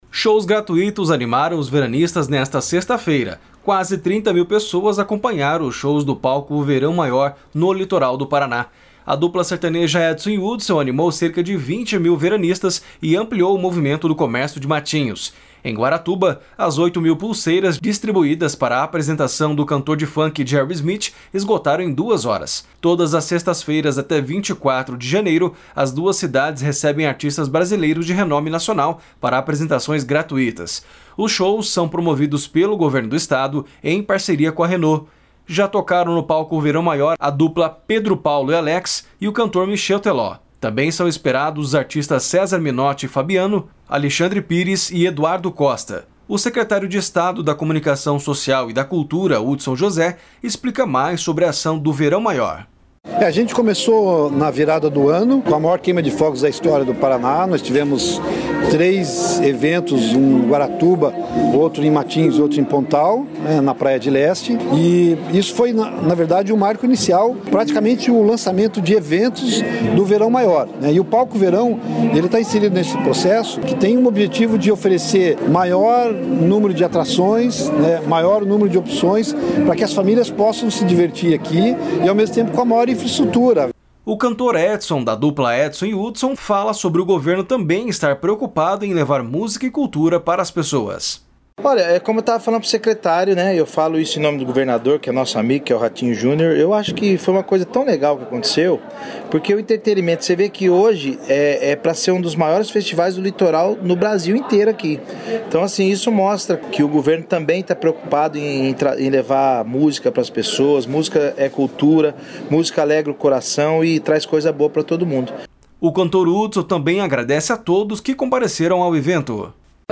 O secretário de Estado da Comunicação Social e da Cultura, Hudson José, explica mais sobre a ação do Verão Maior.
//SONORA EDSON// O cantor Hudson também agradece a todos que compareceram ao evento.